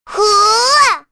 Rehartna-Vox_Attack6.wav